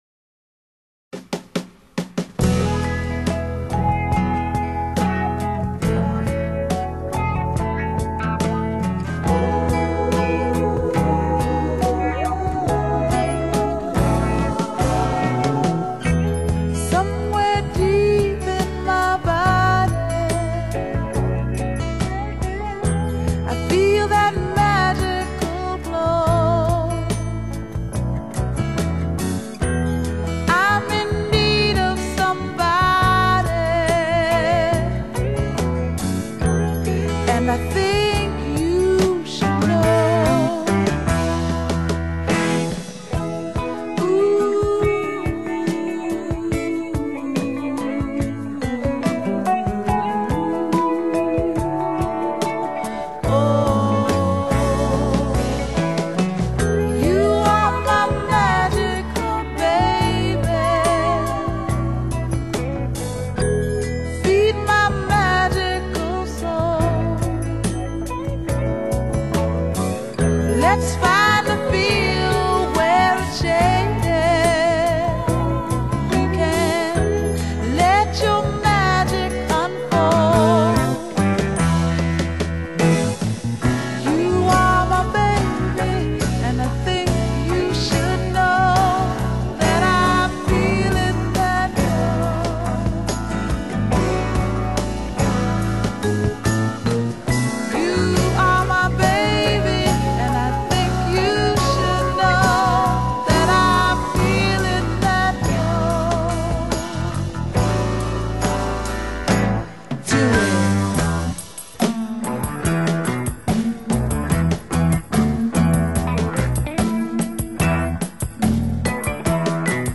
R&B Soul, Soft Rock, Vocal